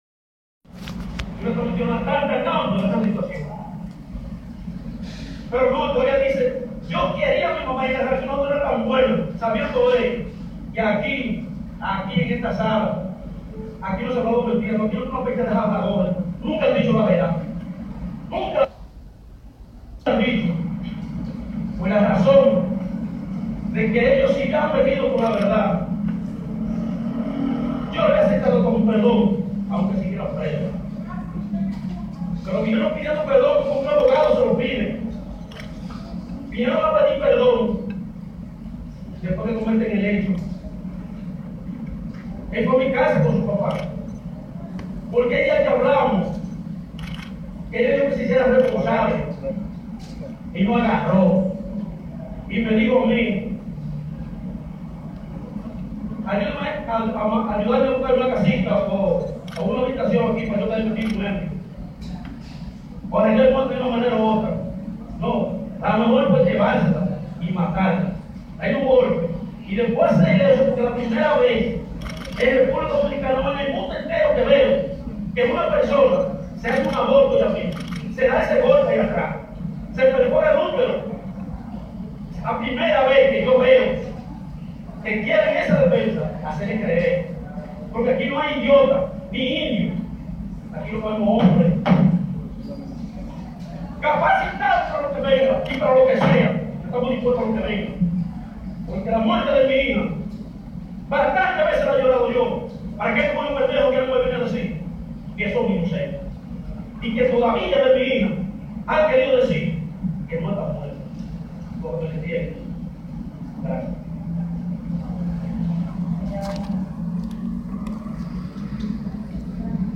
Visiblemente conmovida, con lágrimas en los ojos y gemidos durante toda su intervención